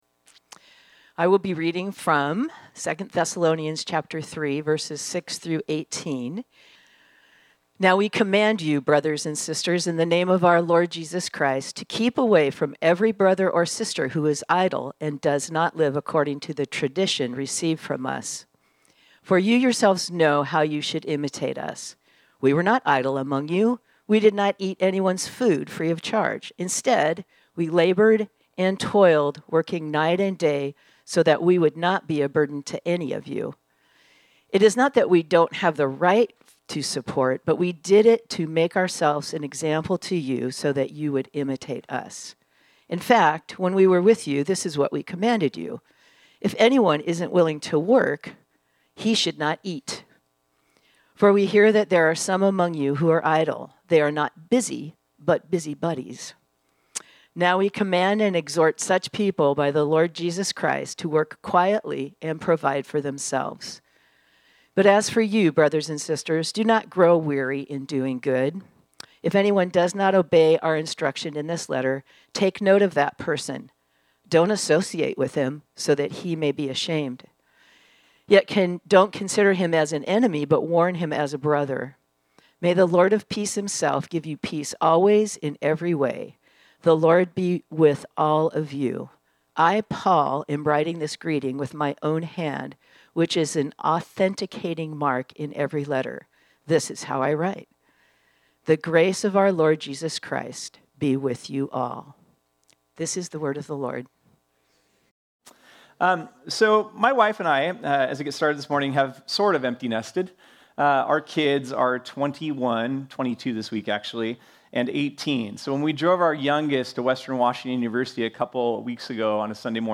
This sermon was originally preached on Sunday, September 28, 2025.